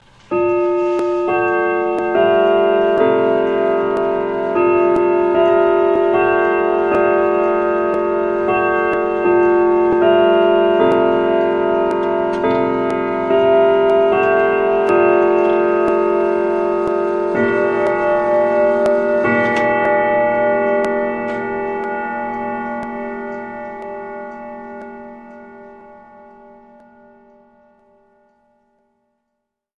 Звон часов
8-дневный завод, мелодия Вестминстер, автоматическое ночное отключение мелодии и боя, автоматическая регулировка равномерности хода, компенсационный маятник, эмалевый циферблат с дополнительным секундным циферблатом и со стрелками в стиле Бреге, ограненное стекло, лакированная цельнолатунная фурнитура.
comitti-longcase-westminster-melody-on-rods.mp3